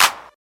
JJClap (9).wav